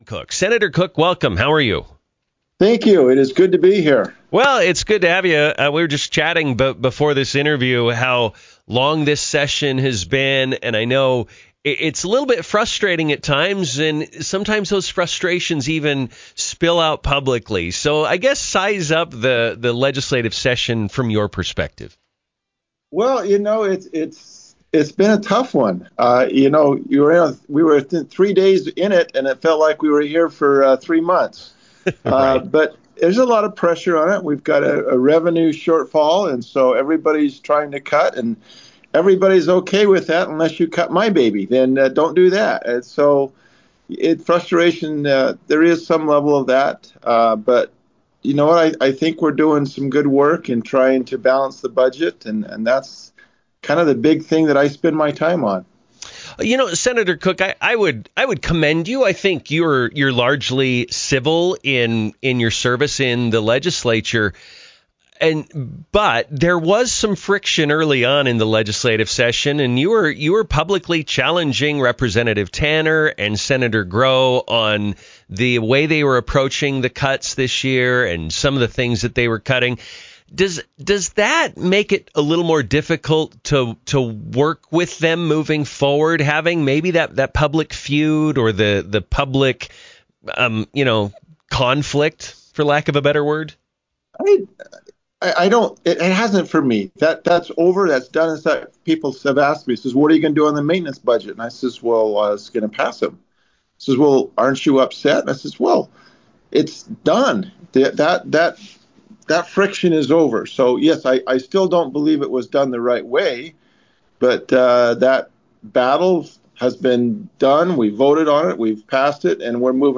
INTERVIEW: Sen. Kevin Cook on Budget, Legislative Session - Newstalk 107.9